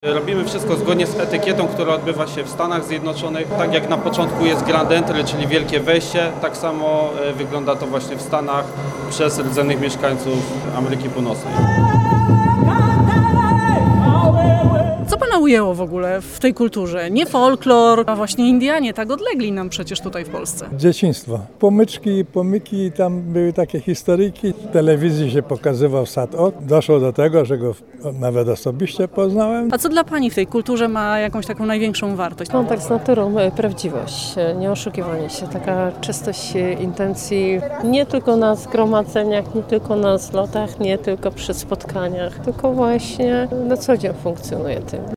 W Uniejowie zorganizowano doroczny Festiwal Muzyki i Tańca Indian Ameryki Północnej Pow Wow.
obrazek-pow-wow-Uniejow24.mp3